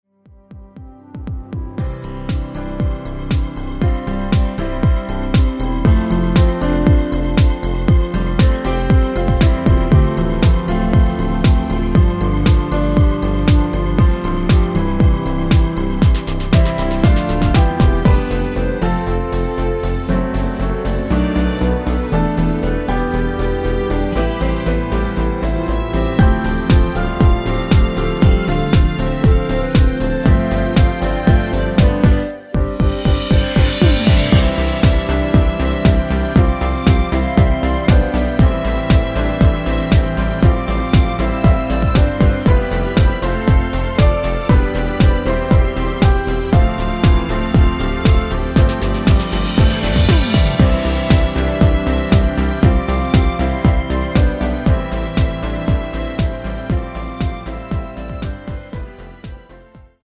전주가 없는 곡이라 2마디 전주 만들어 놓았습니다.
◈ 곡명 옆 (-1)은 반음 내림, (+1)은 반음 올림 입니다.
노래방에서 노래를 부르실때 노래 부분에 가이드 멜로디가 따라 나와서
앞부분30초, 뒷부분30초씩 편집해서 올려 드리고 있습니다.